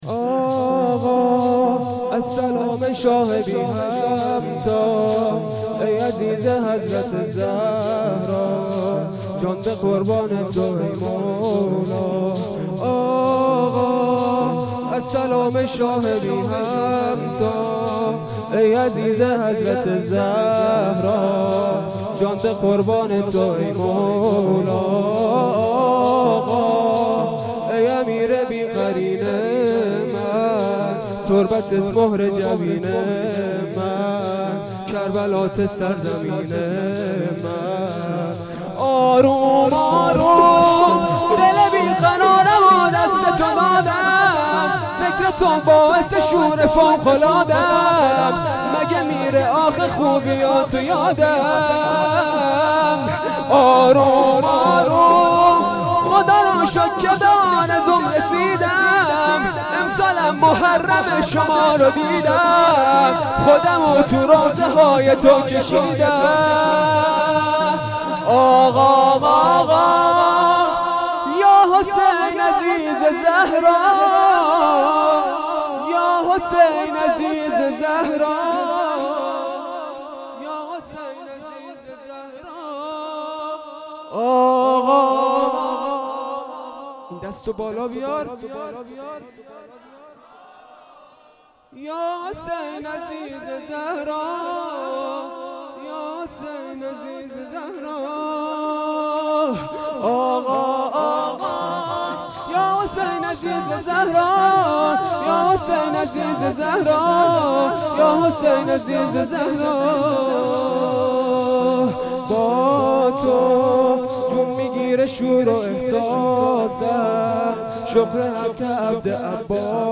شور شب سوم محرم